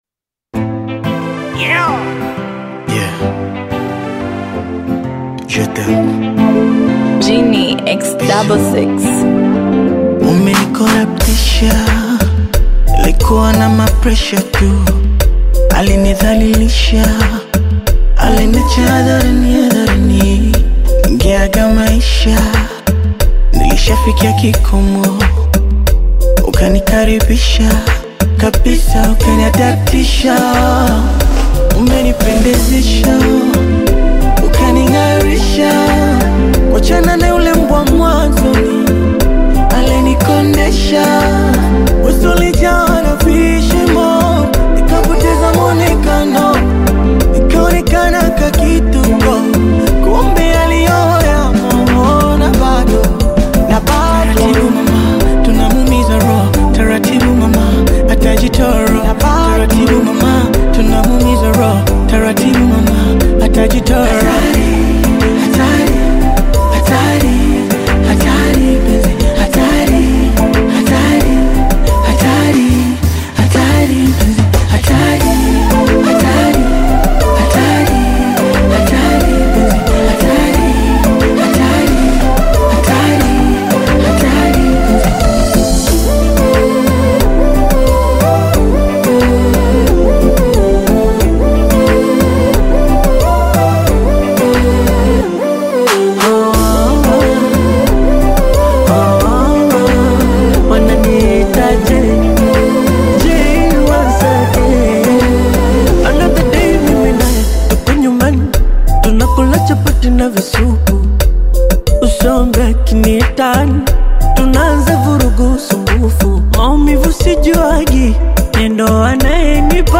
Bongo Flava
Tanzanian Bongo Flava artists